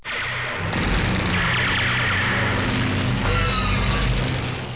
دانلود صدای طیاره 9 از ساعد نیوز با لینک مستقیم و کیفیت بالا
جلوه های صوتی